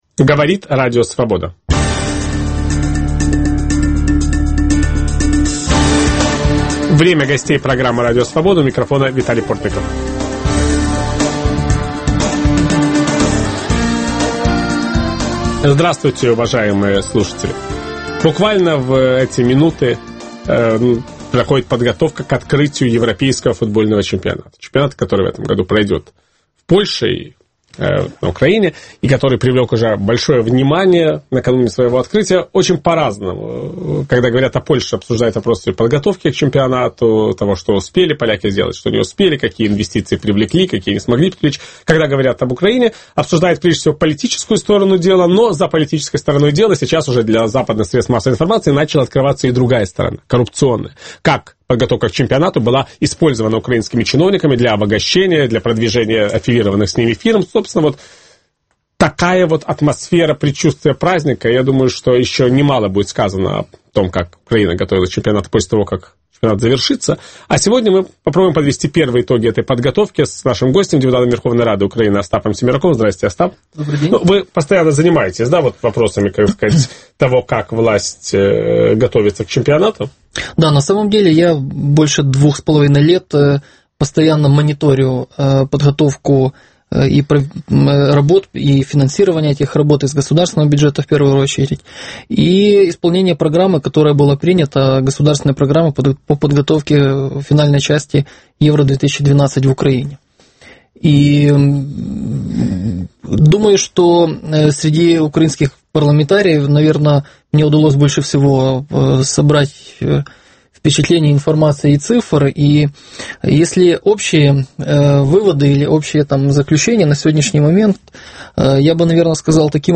Что удалось украинским властям при подготовке к первенству континента? Почему наблюдатели все чаще говорят о коррупции, которой сопровождалась эта подготовка? В программе участвует депутат Верховной рады Украины Остап Семерак.